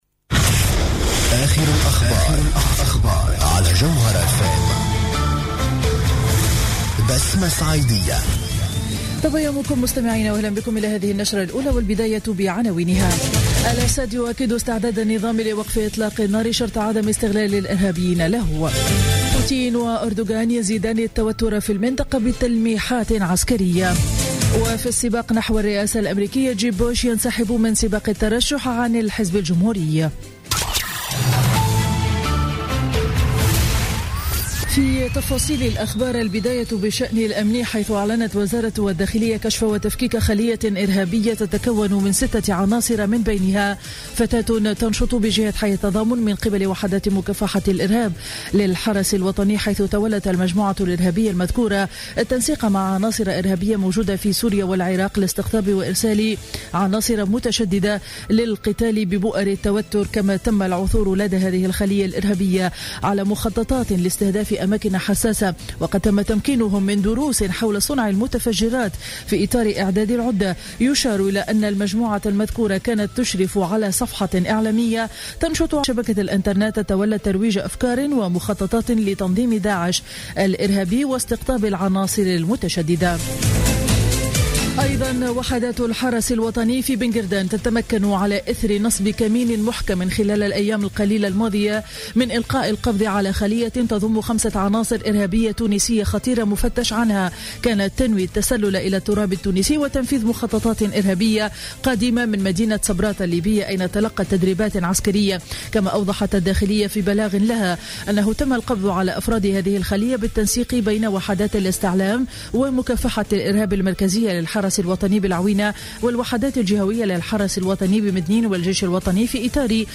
نشرة أخبار السابعة صباحا ليوم الأحد 21 فيفري 2016